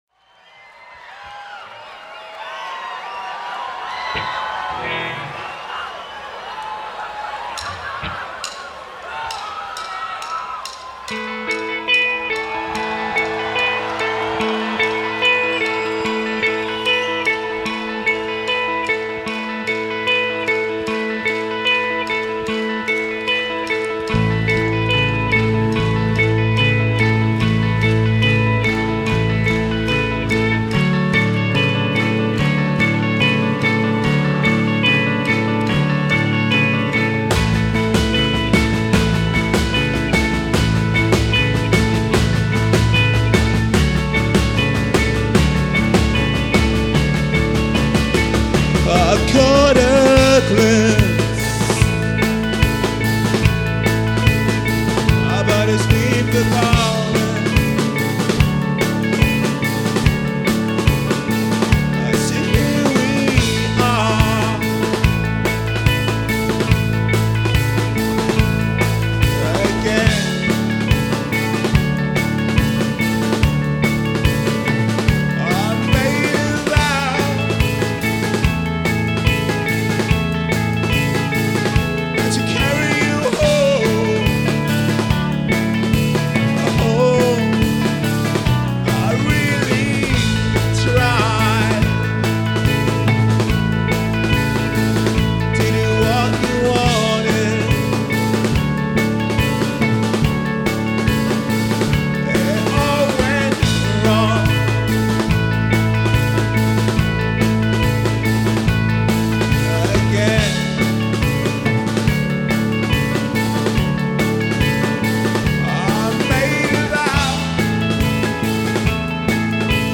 lead guitar
drums, percussion